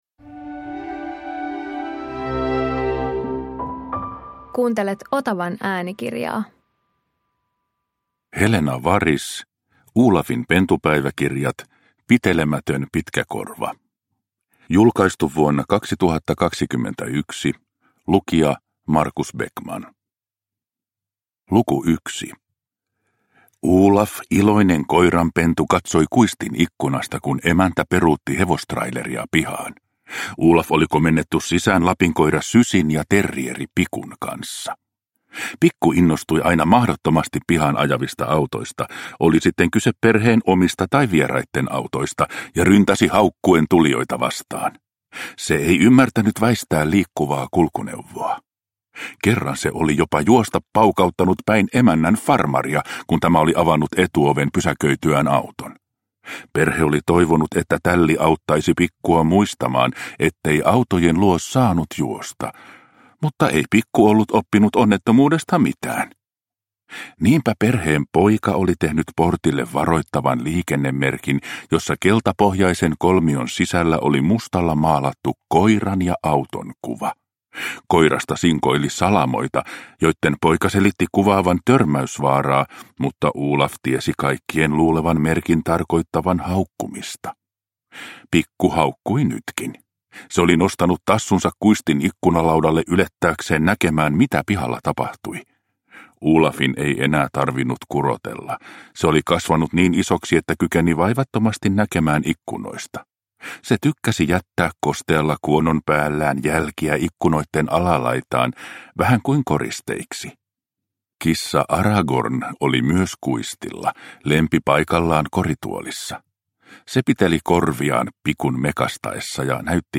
Olafin pentupäiväkirjat - Pitelemätön pitkäkorva – Ljudbok